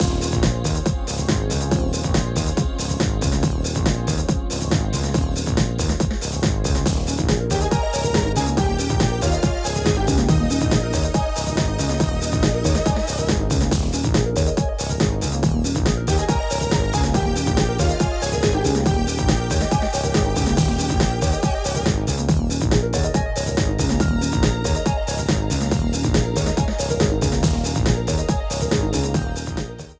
Frontrunning music
30 seconds with fade-out You cannot overwrite this file.